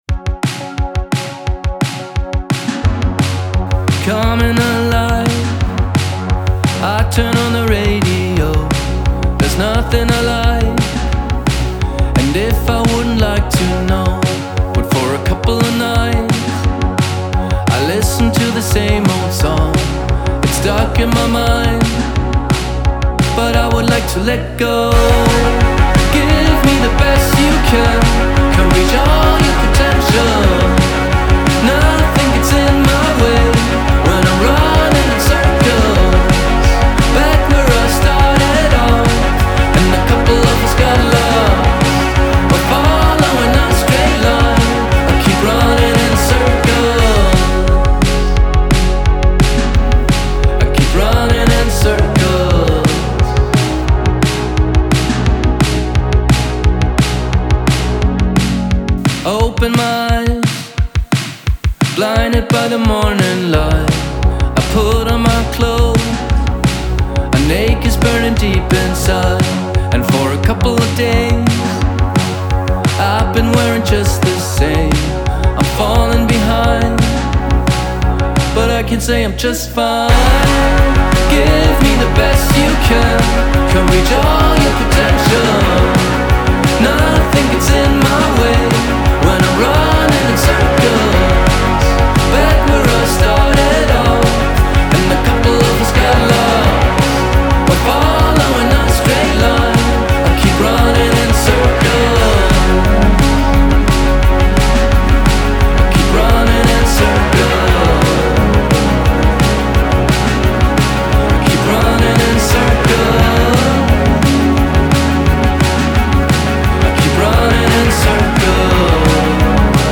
Indie-Rock-Band